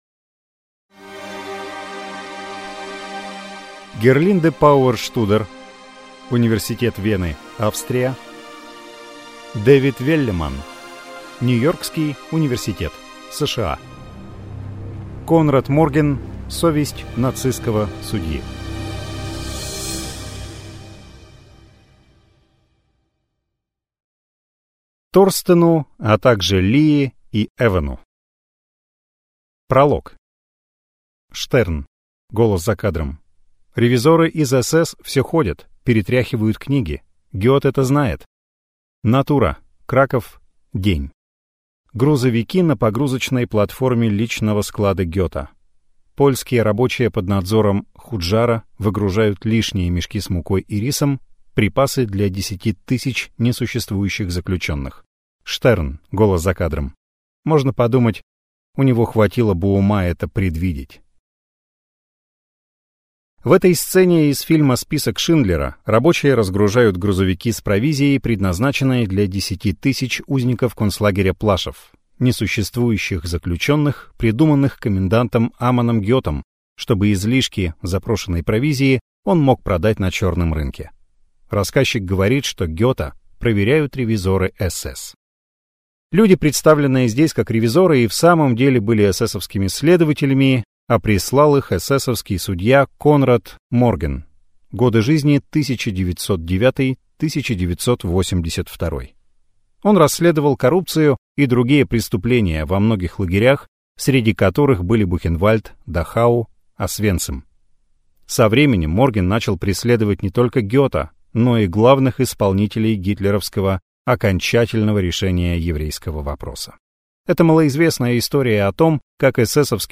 Аудиокнига Конрад Морген: Совесть нацистского судьи | Библиотека аудиокниг